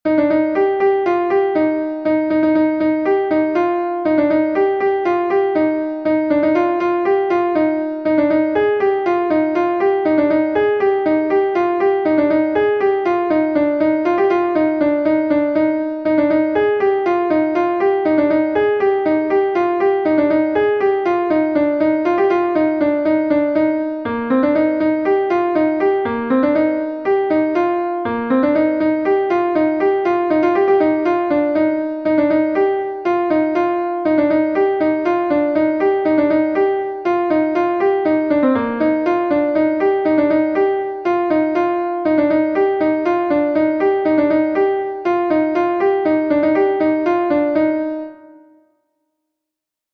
Gavotenn Er Hroesti III is a Gavotte from Brittany